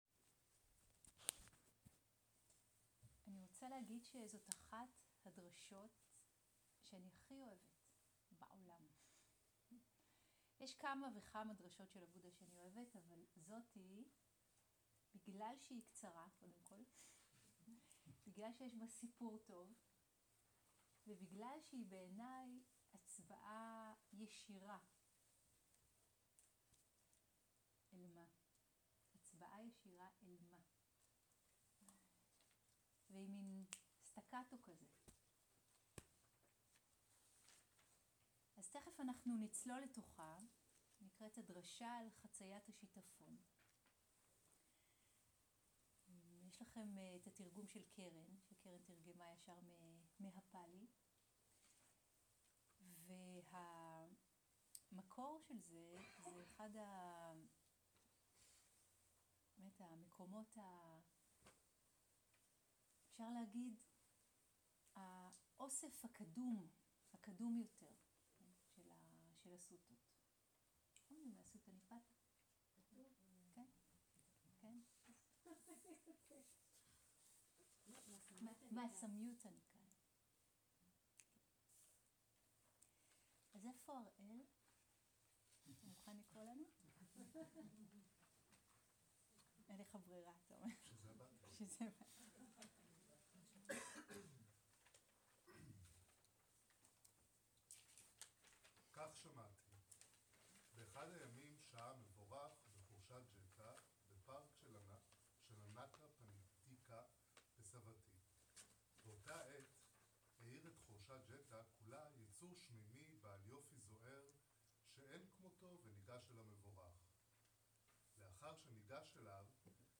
סוג ההקלטה: שיחות דהרמה
עברית איכות ההקלטה: איכות גבוהה מידע נוסף אודות ההקלטה